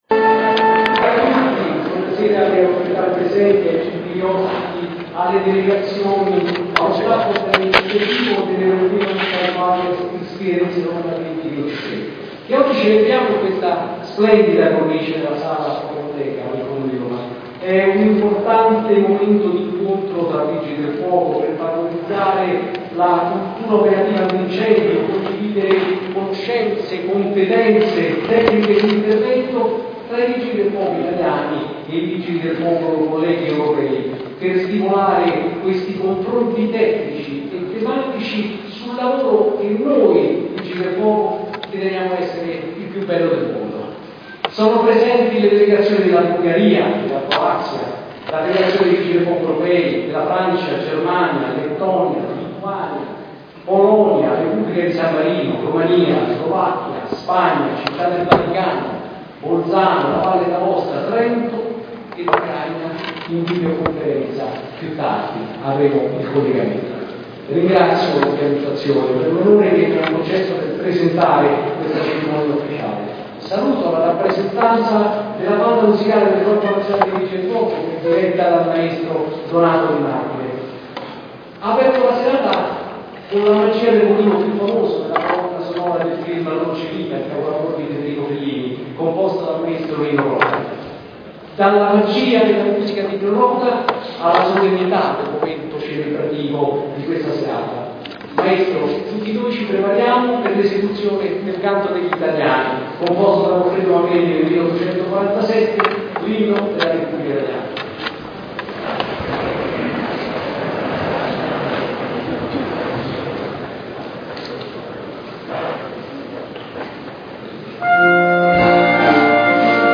A528 – SICUREZZA, EVENTI: I VIGILI DEL FUOCO EUROPEI A ROMA. La cerimonia di inaugurazione dell’evento, che riceve la main sponsorship da Fiera Roma, ha avuto luogo nella Sala della Protomoteca in Campidoglio nel pomeriggio di ieri.
Erano presenti il sindaco di Roma Capitale ROBERTO GUALTIERI, i vertici del Corpo nazionale dei Vigili del fuoco, il sottosegretario al Ministero dell’Interno WANDA FERRO e numerose delegazioni di vigili del fuoco convenute dalle capitali europee.